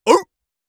seal_walrus_bark_single_04.wav